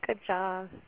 Seven prosodic variants of good job (au files):
vibrato
vibrato.au